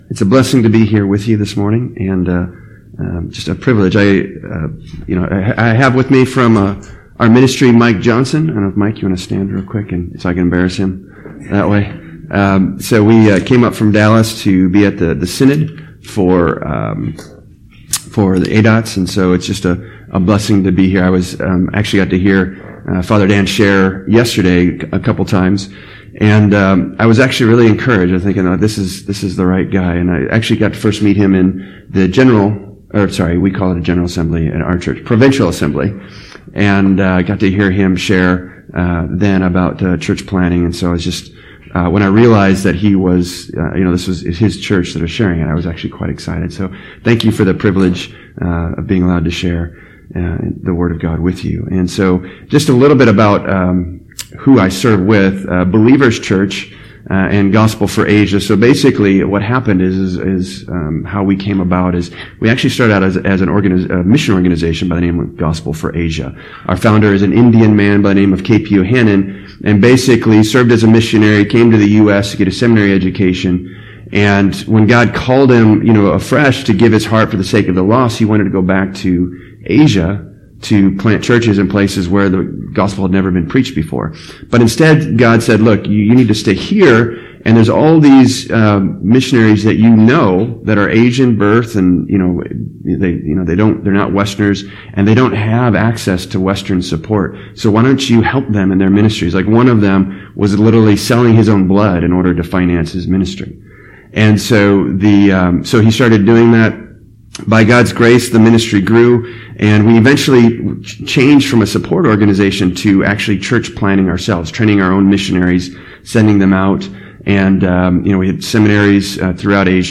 In this sermon, the speaker encourages the audience to invest their hearts and minds in spreading the gospel to a world in need.